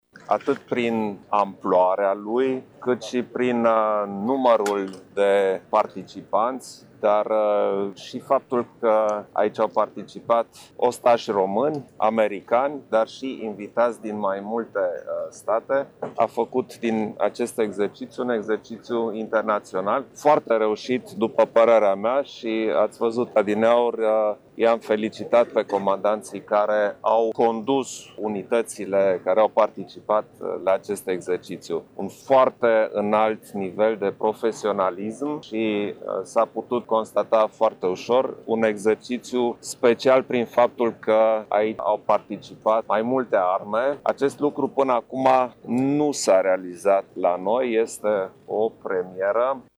Șeful statului s-a arătat încântat de reușita exercițiului, i-a felicitat pe comandanții militari și a ținut să sublinieze că un exercițiu de o asemenea amploare și complexitate este o premieră pentru țara noastră: